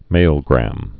(mālgrăm)